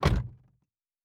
Car Door (2).wav